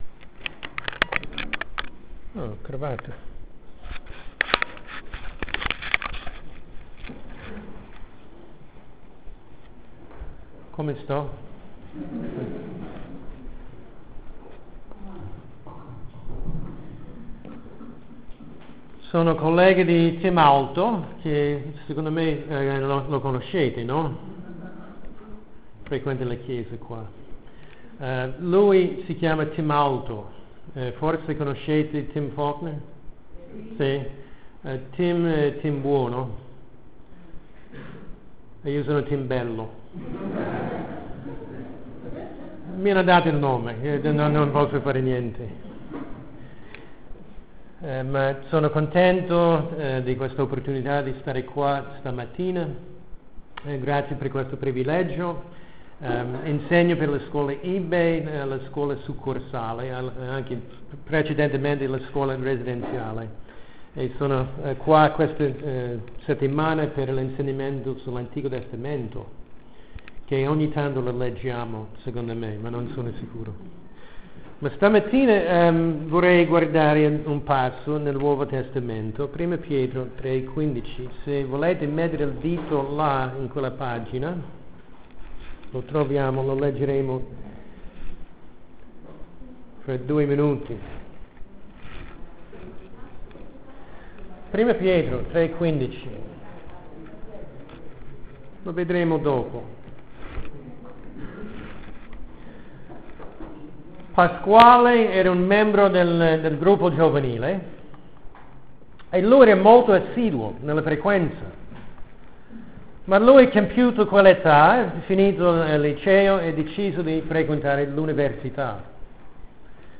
Predicazione